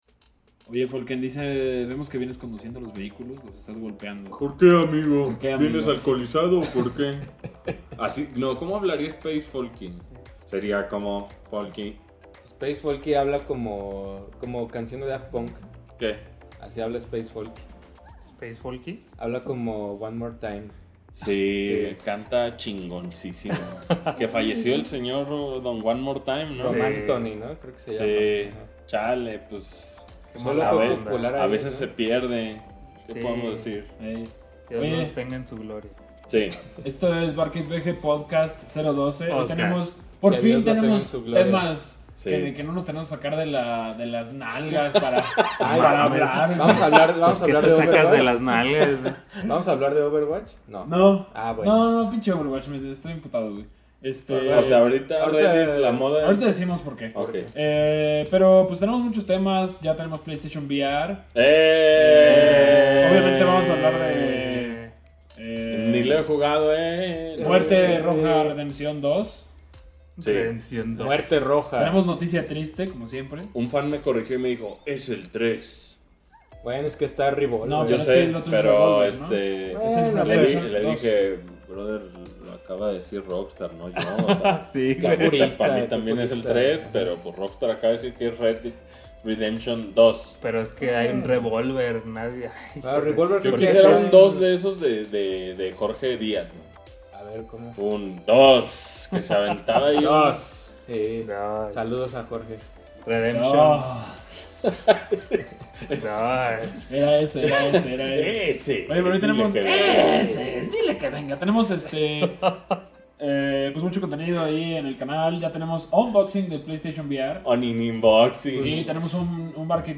Muchos rumores de Red Dead Redemption 2, noticias actuales de la industria de videojuegos y nuestras impresiones sobre el PlayStation VR, siempre acompañado de risas y el mejor staff.